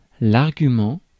Ääntäminen
Ääntäminen France Tuntematon aksentti: IPA: /aʁ.ɡy.mɑ̃/ Haettu sana löytyi näillä lähdekielillä: ranska Käännöksiä ei löytynyt valitulle kohdekielelle.